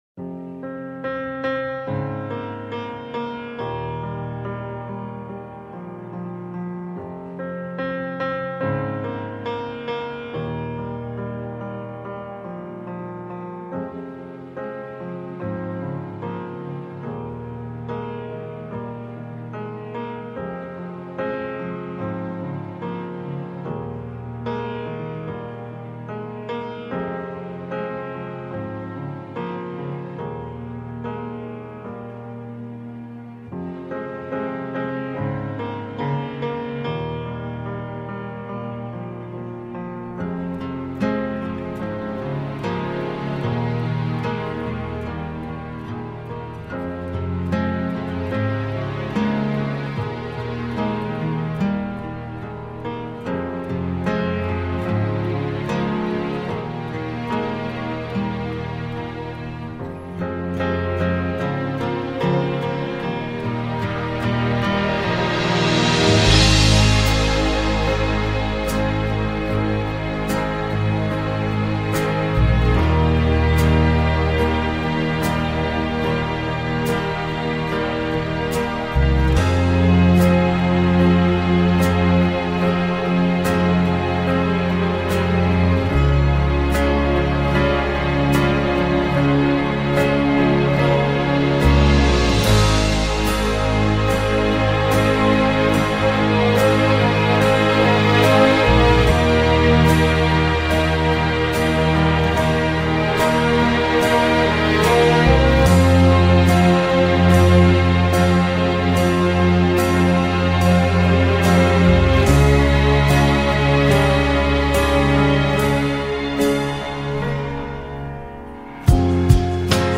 Pop Instrumental